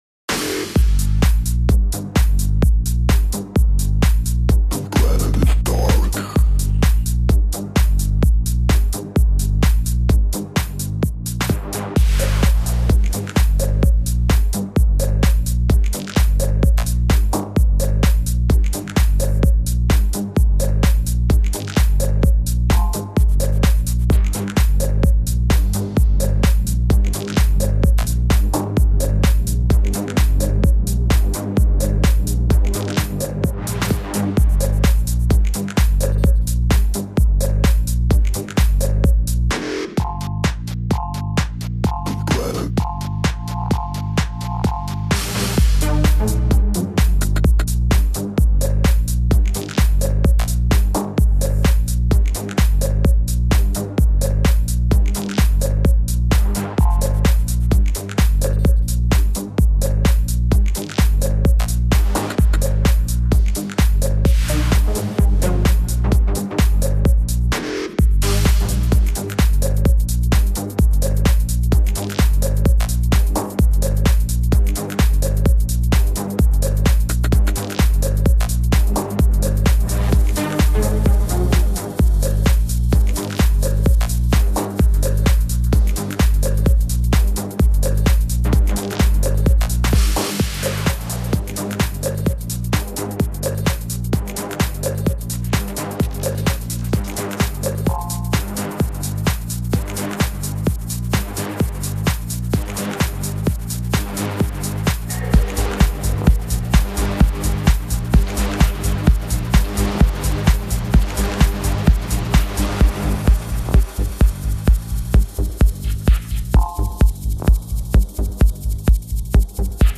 Minimal Tech